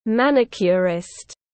Thợ làm móng tiếng anh gọi là manicurist, phiên âm tiếng anh đọc là /ˈmænɪkjʊrɪst/.